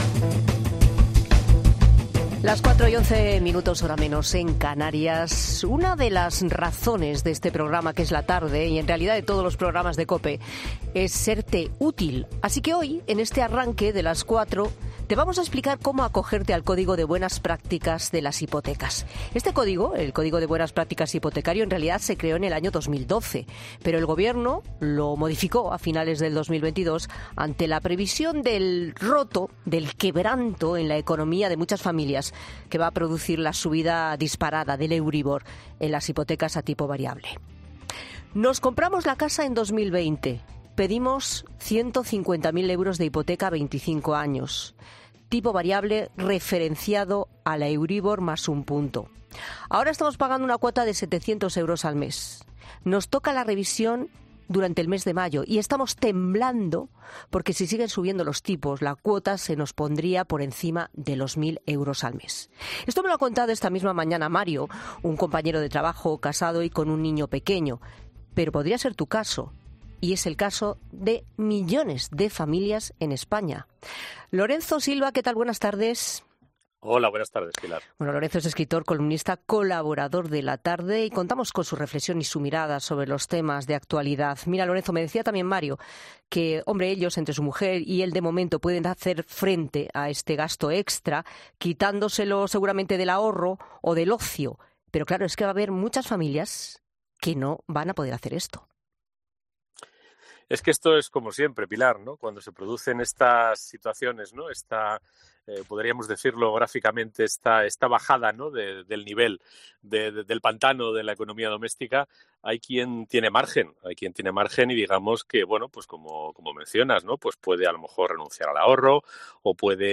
En La Tarde nos acompaña hoy Lorenzo Silva, escritor, columnista y colaborador de nuestro programa.